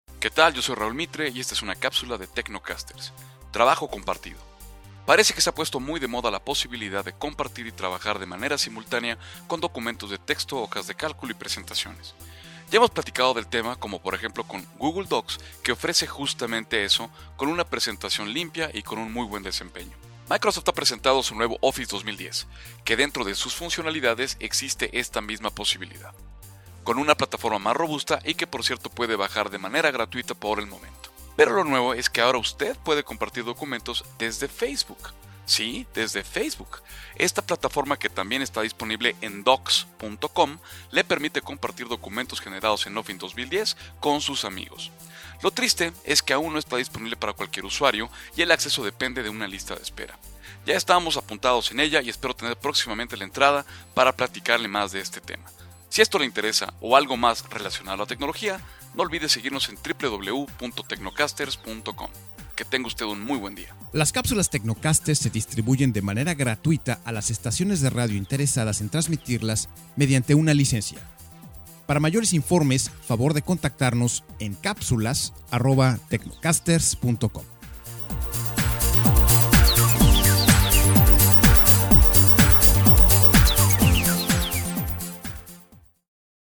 La moda sera poder colaborar linea con sus amigos...por Facebook! - Capsula para Transmision en Radio